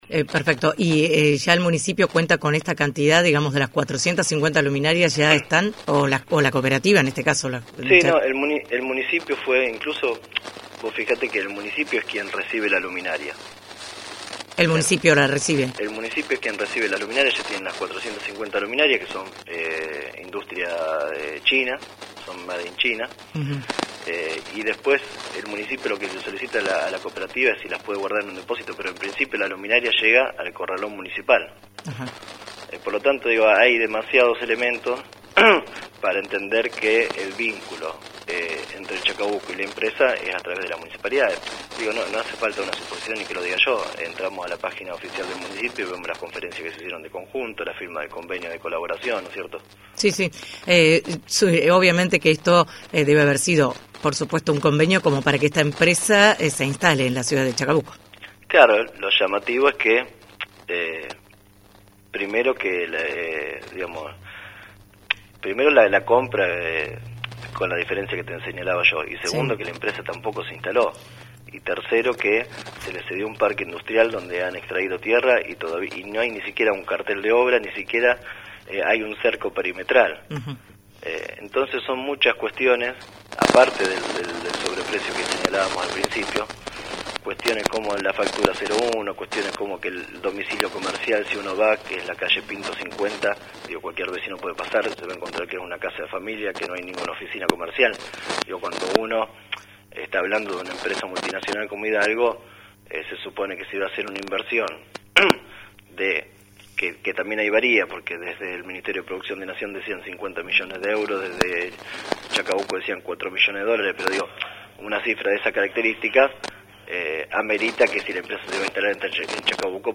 Audio nota radial